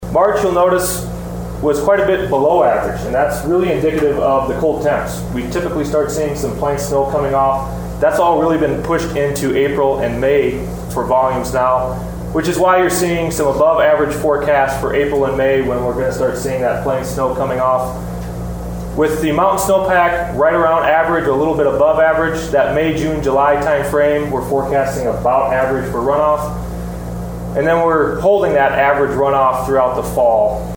at a meeting in Bismarck